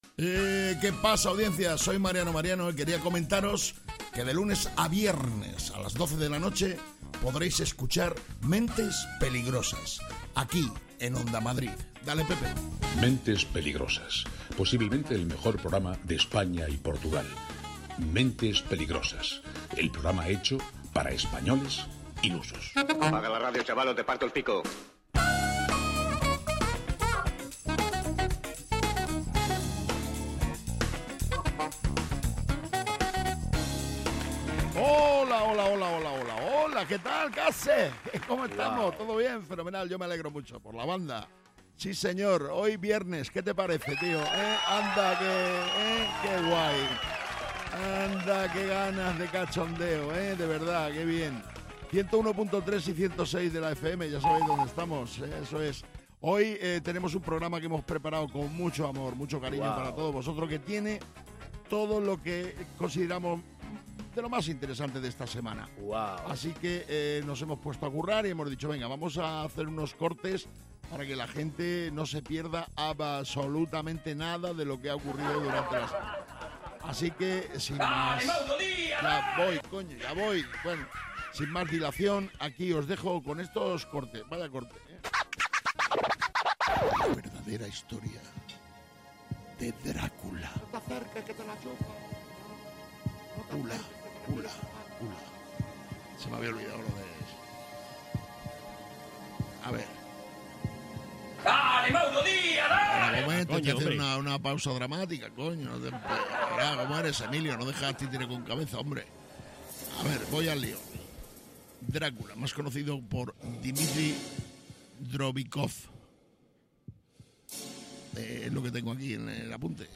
Mentes Peligrosas es humor, y quizás os preguntaréis, ¿y de qué tipo de humor es?, pues del que te ríes, porque si no, podría ser una bicicleta, un destornillador, cualquier cosa.